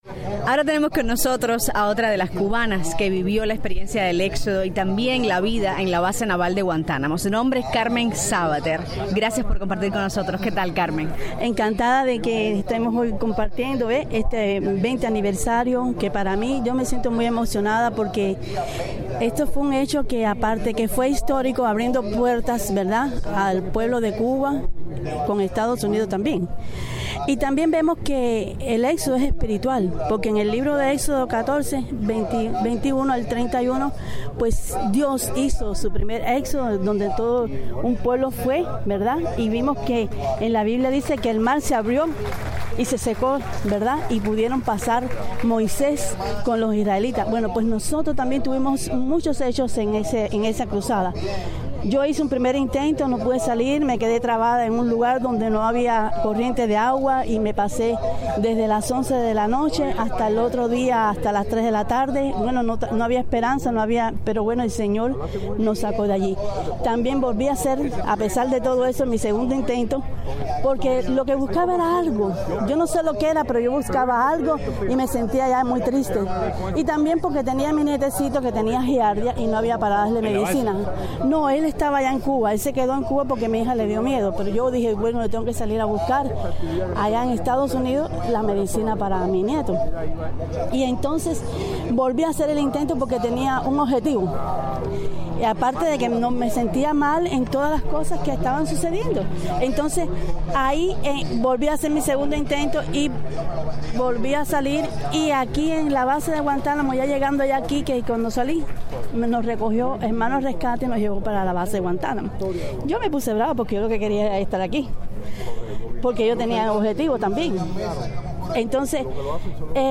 Reunión de la Fundación Exodo 94
En cualquier caso, la reunión de este 20 de agosto en el parque Amelia Earhart de Hialeah, ha sido la ocasión propicia para reencontrarse y sacarse del pecho todas esas historias que han contado a pedacitos ya por dos décadas.